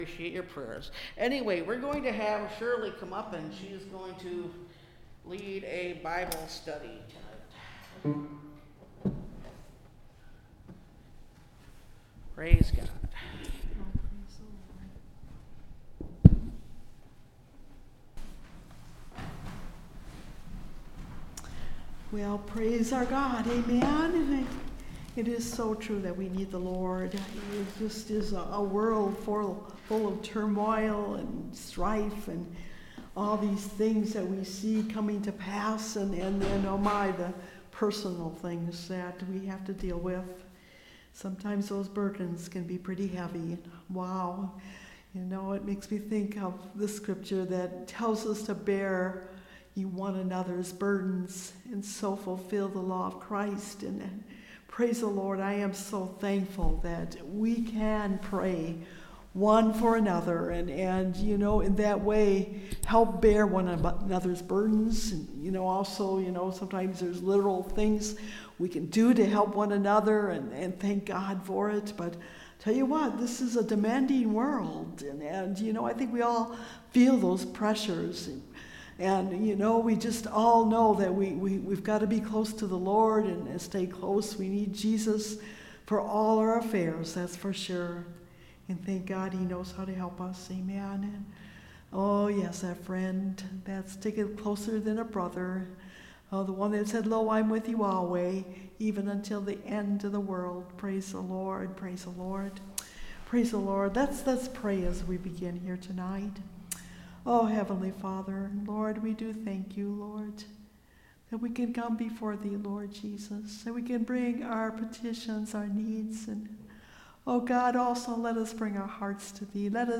They Turned The World Upside Down – Part 6 (Message Audio) – Last Trumpet Ministries – Truth Tabernacle – Sermon Library
Service Type: Wednesday Night Bible Study